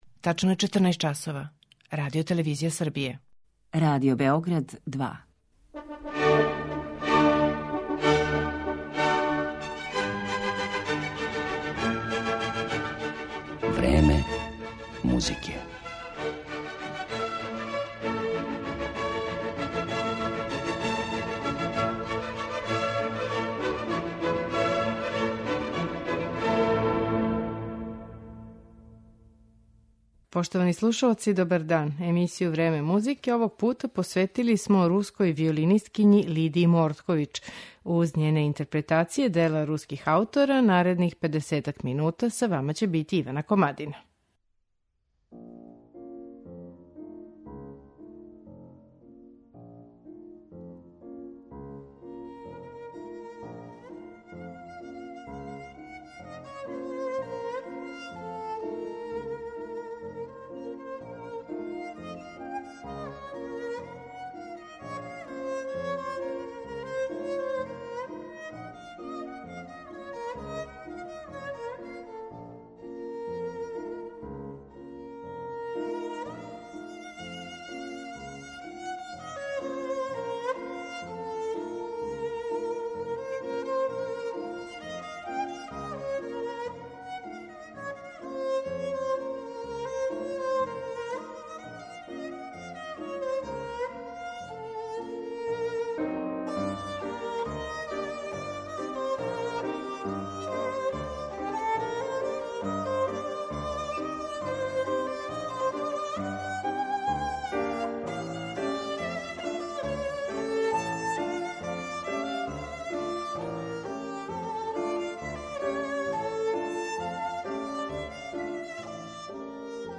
Музички портрет Лидије Мордкович